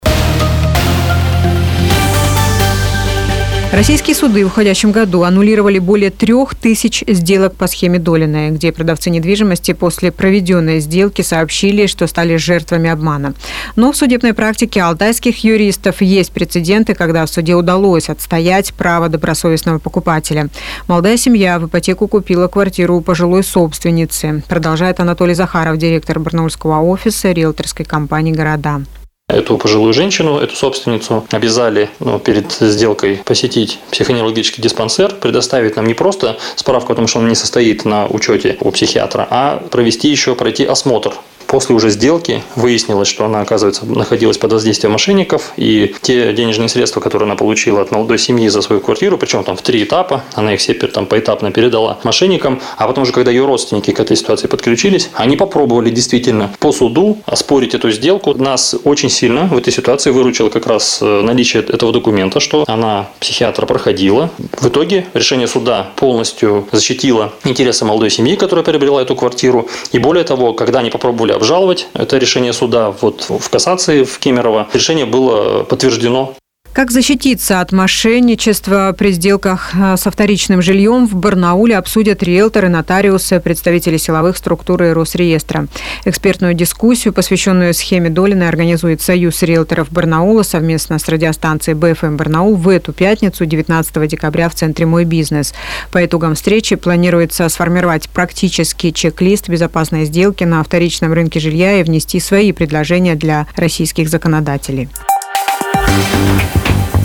Сюжет на Business FM (Бизнес ФМ) Барнаул
shema_dolinoy_sudebnaya_praktika_kruglyiy_stol.mp3